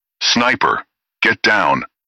sniper get down.wav